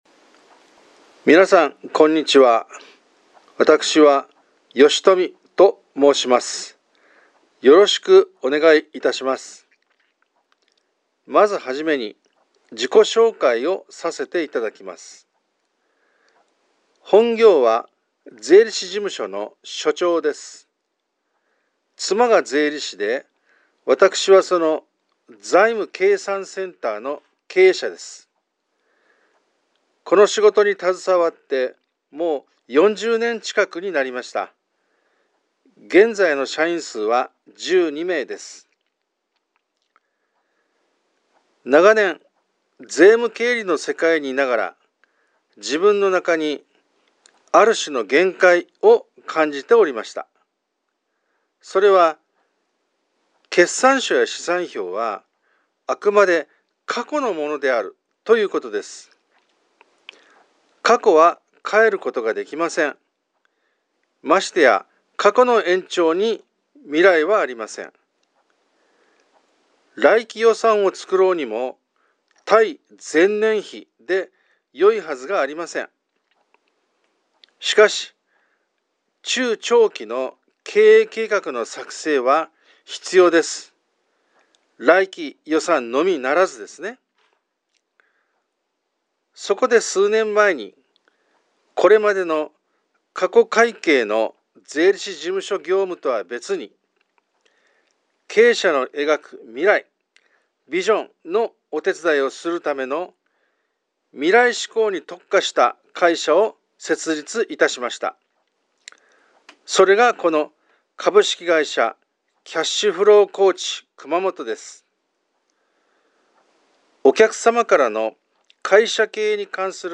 しかし聞いてみてビックリ。素人の私がハマリ込む程に、優しく・丁寧な語り口と、分かり易い図を多用してあることに、大いに安心できました。